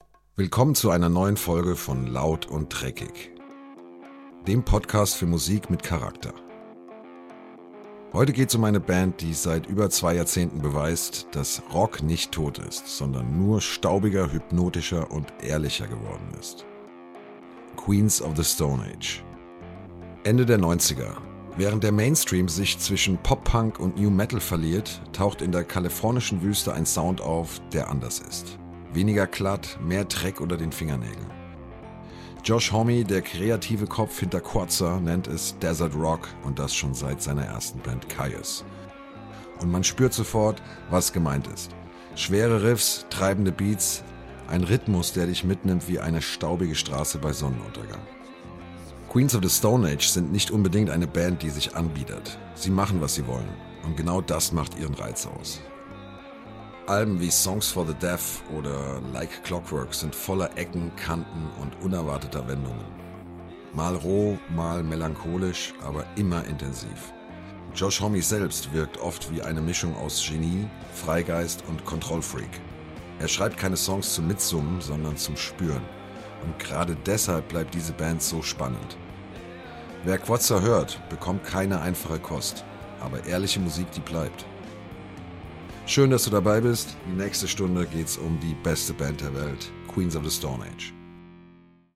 Male
My voice ranges from warm and deep to calm and engaging, making it appropriate for a variety of projects.
Podcasting
German Podcast 1